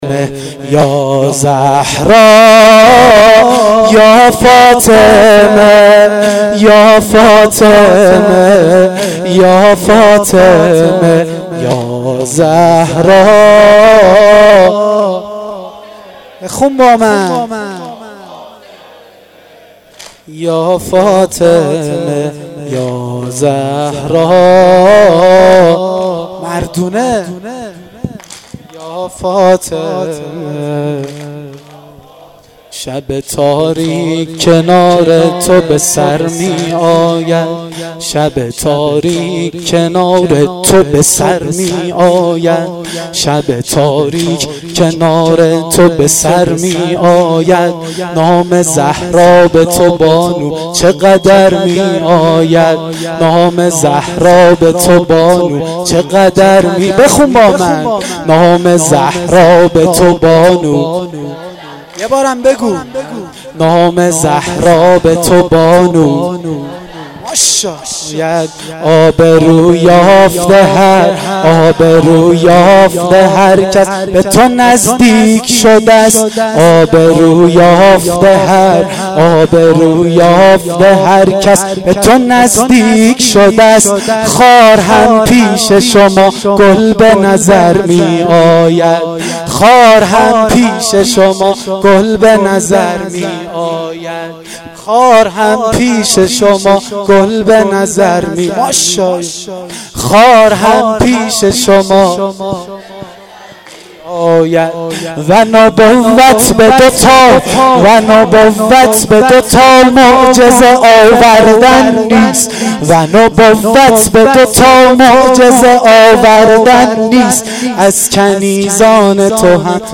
واحد شب اول فاطمیه